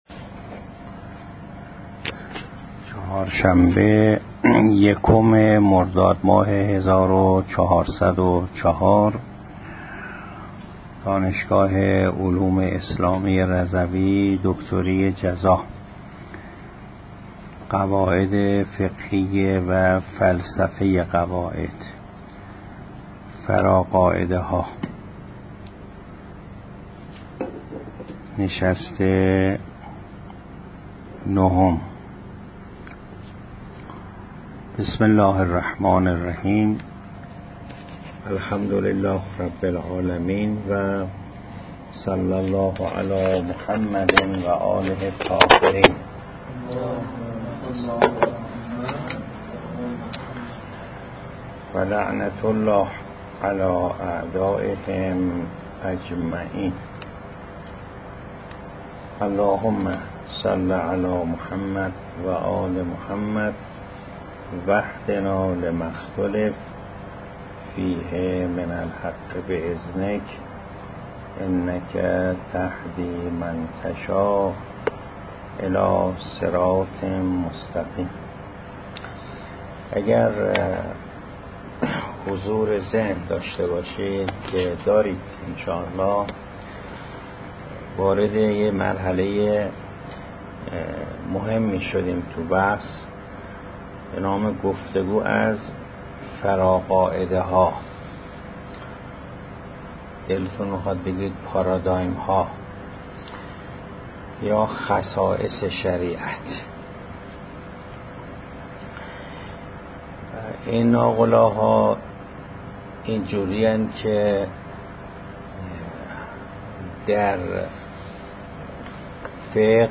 دانشگاه علوم اسلامی رضوی فلسفه قواعد فقه جلسه نهم ادامه گفتگو از فراقاعده ها فراقاعده بودن لاحرج همراه با کنفرانس دانشجويان